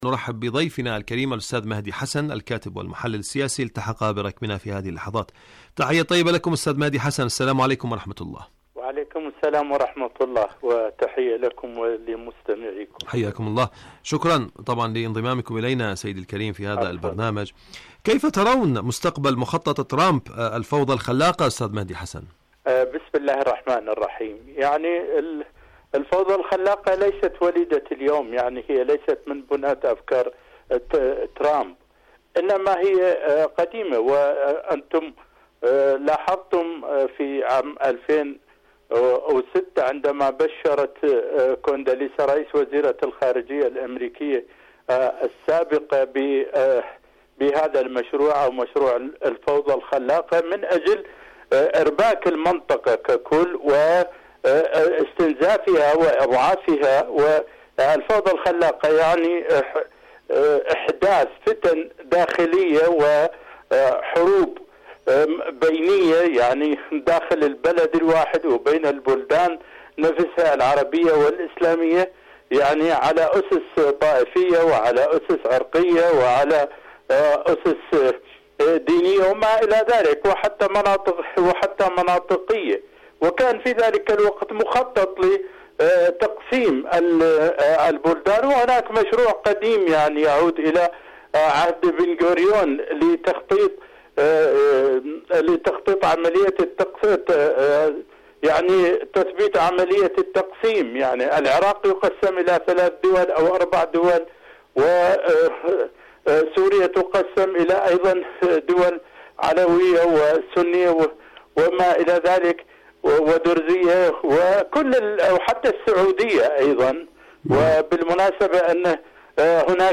مقابلات مقابلات إذاعية برامج إذاعة طهران العربية ترامب وغزة ومحاولات التآمر اليائسة حدث وحوار برنامج حدث وحوار شاركوا هذا الخبر مع أصدقائكم ذات صلة آليات إيران للتعامل مع الوكالة الدولية للطاقة الذرية..